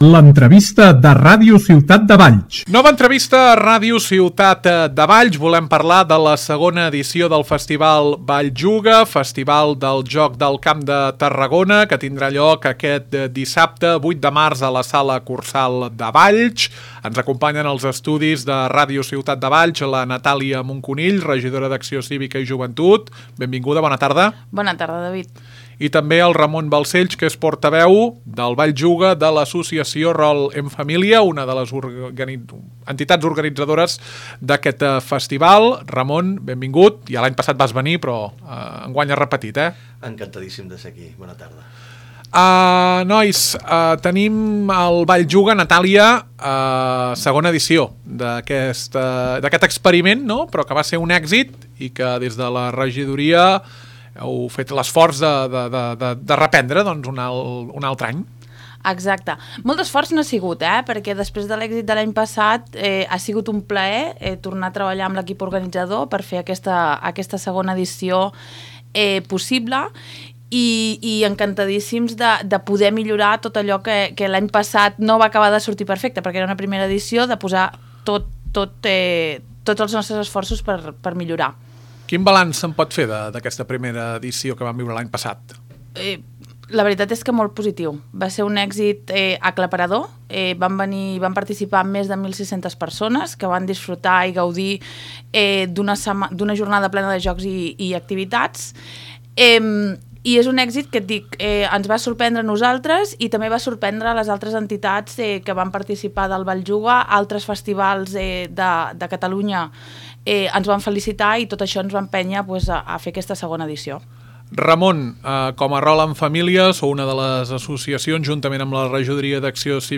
Nova entrevista a Ràdio Ciutat de Valls. Tractem la segona edició del Valls Juga, el festival del Joc del Camp de Tarragona, que se celebrarà aquest dissabte 8 de març a la sala Kursaal.